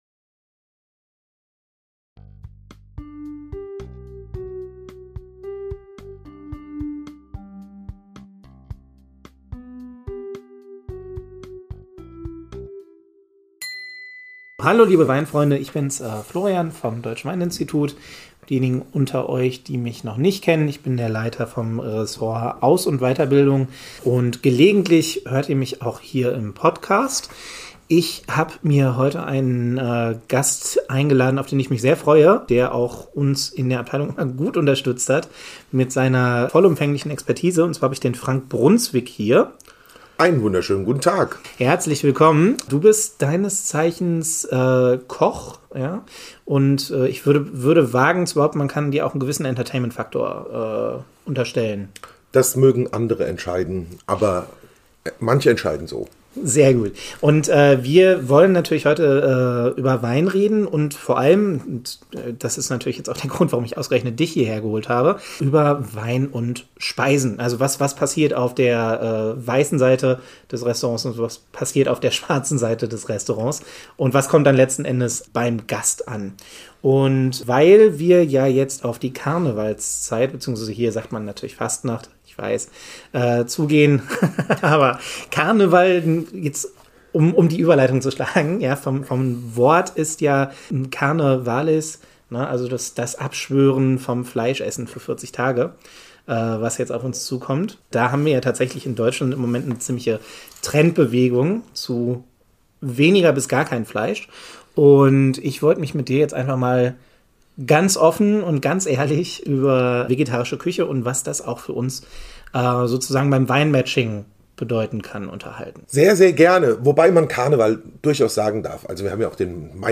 Wie beim Wein gibt es auch beim Essen immer neue Trends und Entwicklungen. Im Gespräch mit Fernsehkoch